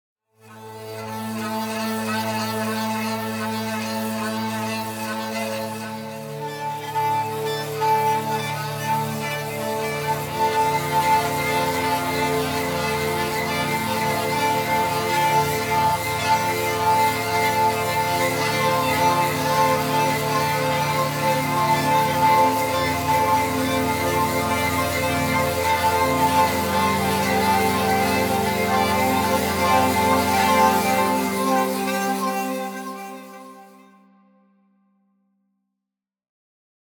There are lovely scratchy bowed sounds that percolate with sizzling harmonics.
Below are a few sound examples recorded without any post-processing effects.
Using Stir and Titan sources